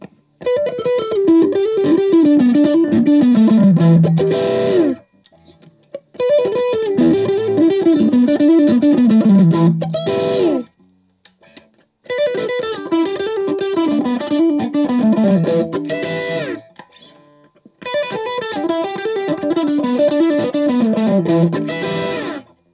All recordings in this section were recorded with an Olympus LS-10.
All guitar knobs are on 10 for all recordings.
Open E chord
For each recording, I cycle through all of the possible pickup combinations, those being (in order): neck pickup, both pickups (in phase), both pickups (out of phase), bridge pickup.